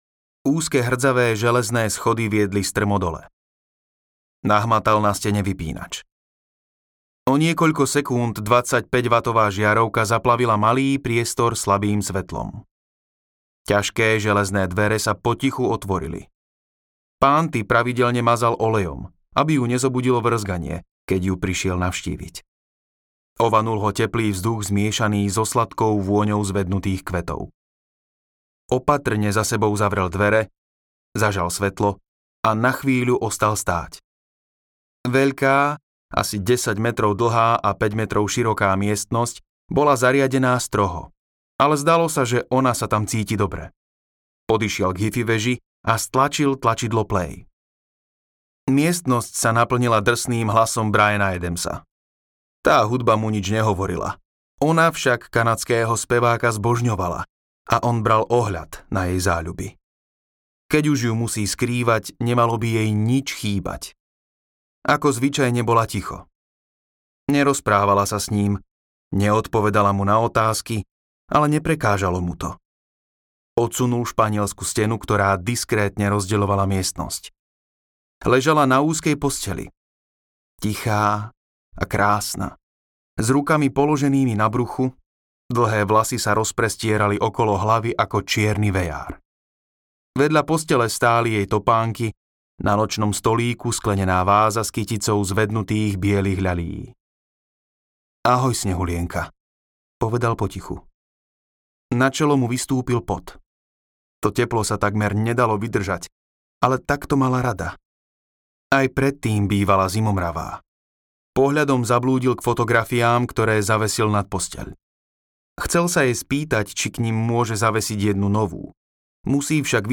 Snehulienka musí zomrieť audiokniha
Ukázka z knihy
snehulienka-musi-zomriet-audiokniha